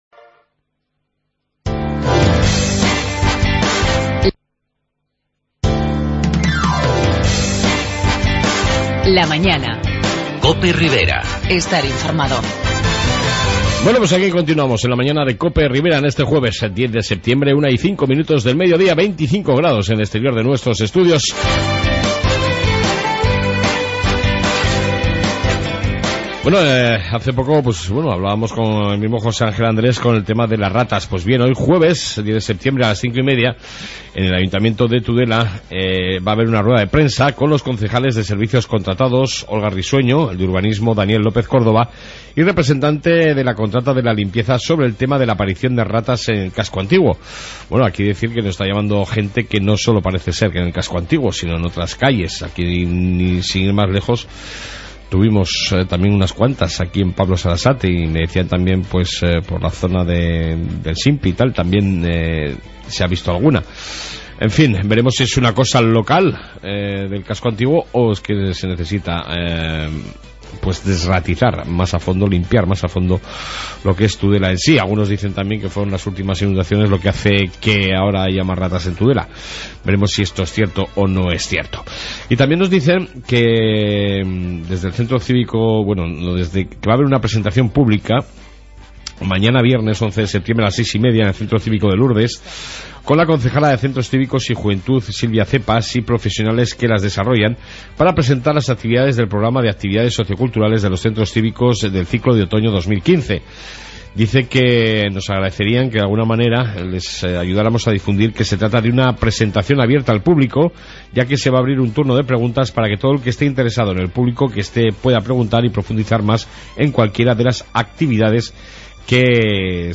Noticias locales y entrevista